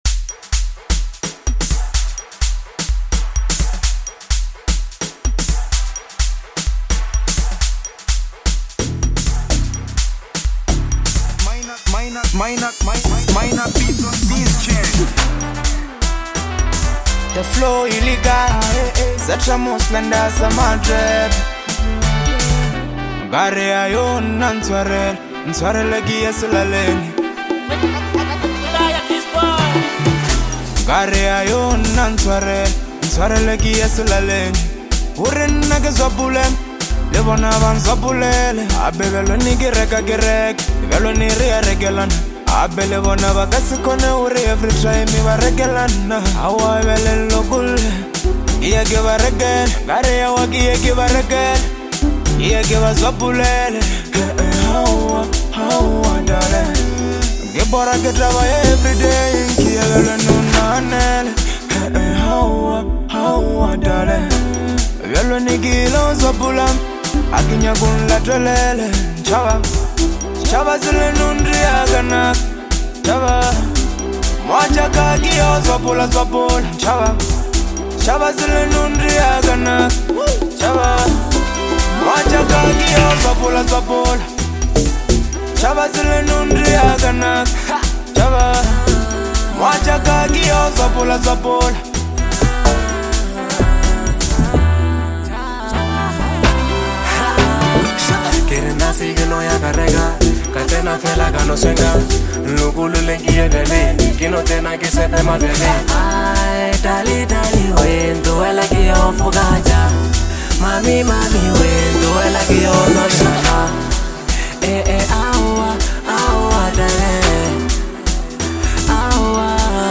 deeply reflective lekompo track